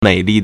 美丽 (美麗) měilì
mei3li4.mp3